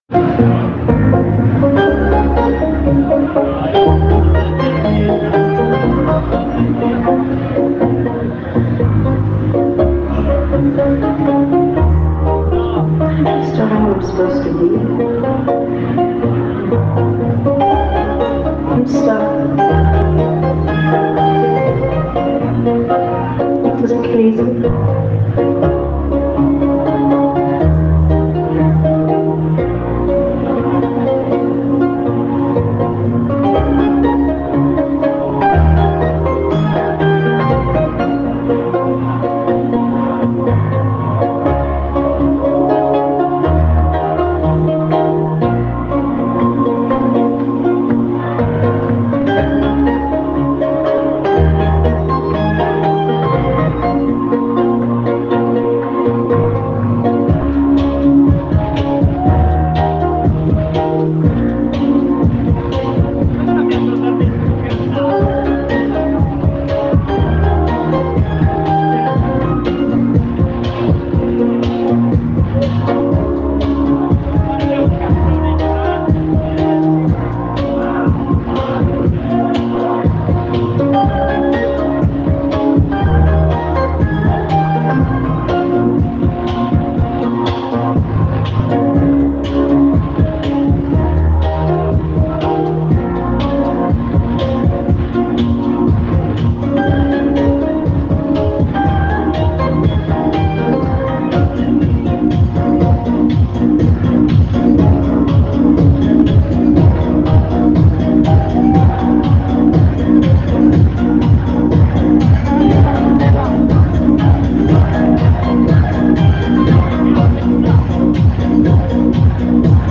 C'è da capire questo bel momento atmosfera che c'è stato.